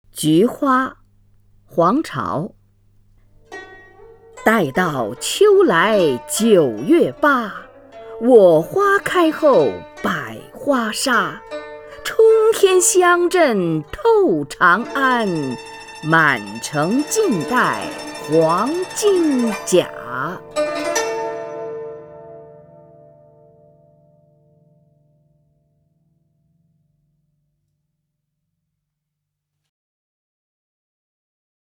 虹云朗诵：《菊花》(（唐）黄巢) （唐）黄巢 名家朗诵欣赏虹云 语文PLUS
（唐）黄巢 文选 （唐）黄巢： 虹云朗诵：《菊花》(（唐）黄巢) / 名家朗诵欣赏 虹云